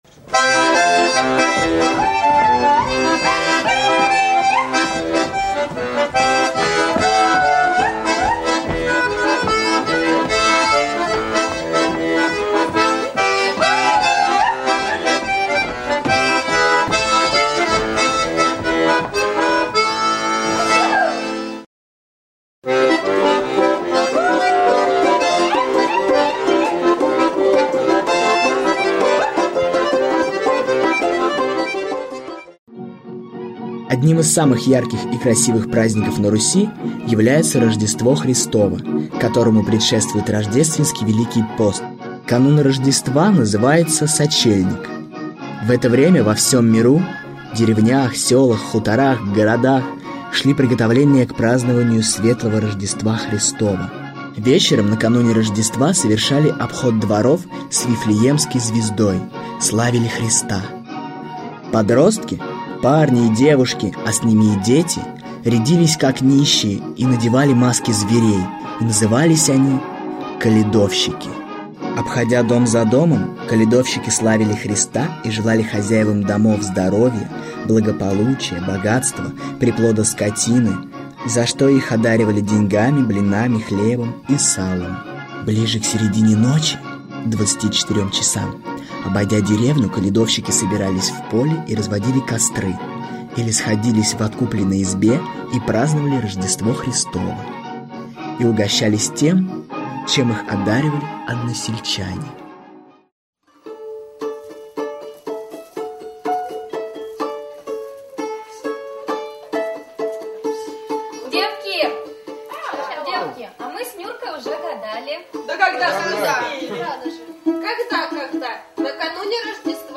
Аудиокнига Русские традиции. Зимние праздники | Библиотека аудиокниг
Зимние праздники Автор Сборник Читает аудиокнигу Актерский коллектив.